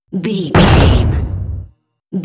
Cinematic drum hit trailer
Sound Effects
Atonal
heavy
intense
dark
aggressive
hits